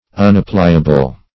Unappliable \Un`ap*pli"a*ble\, a.